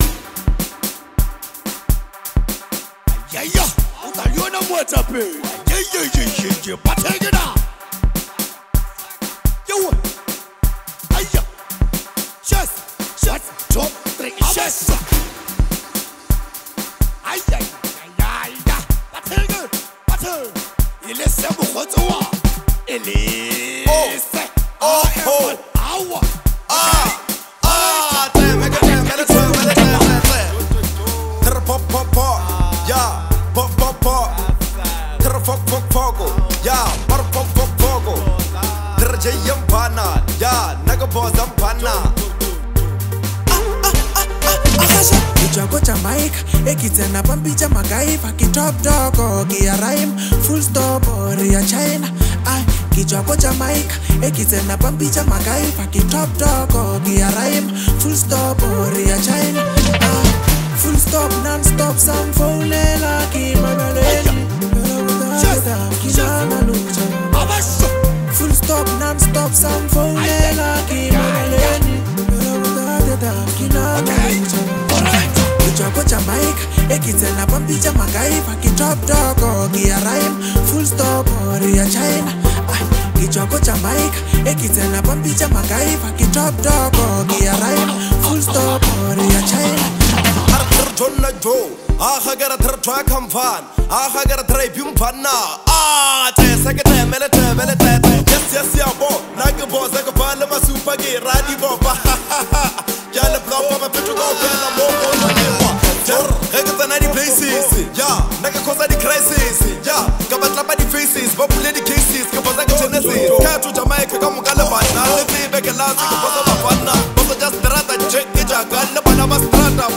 add a chant-like, street-inspired energy,
Lekompo